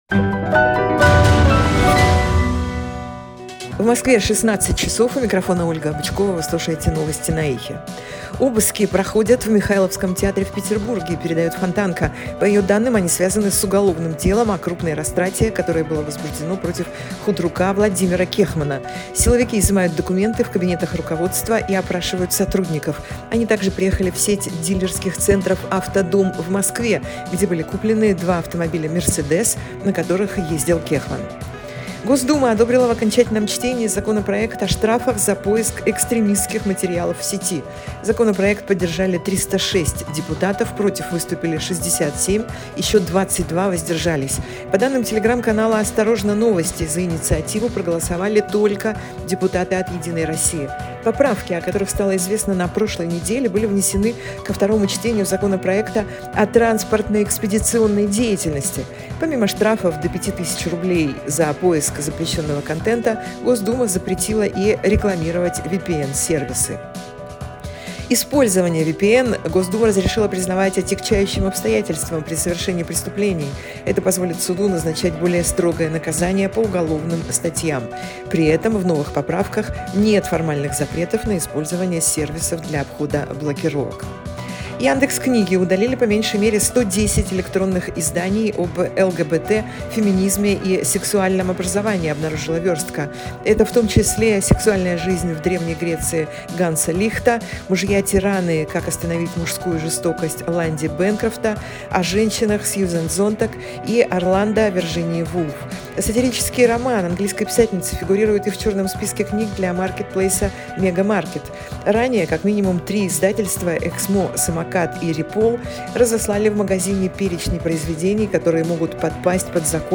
Слушайте свежий выпуск новостей «Эха»
Новости 16:00